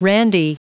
Prononciation du mot randy en anglais (fichier audio)
Prononciation du mot : randy